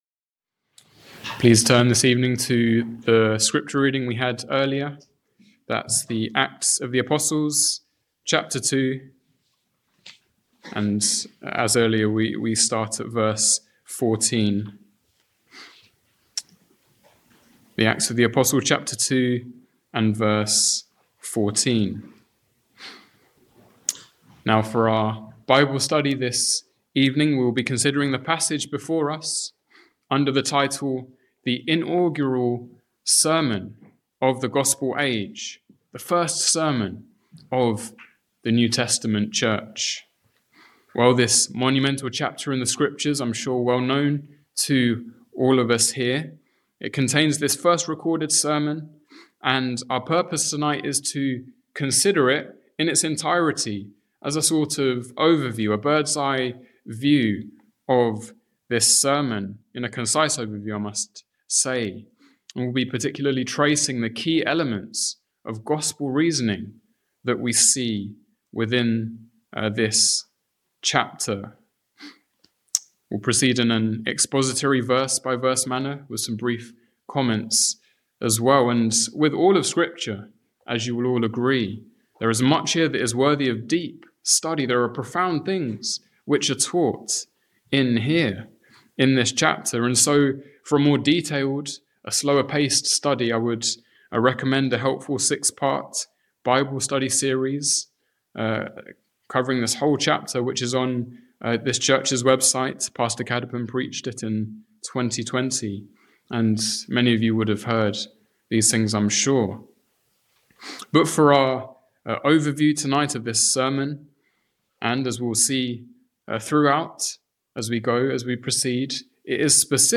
An independent reformed baptist church, founded in 1877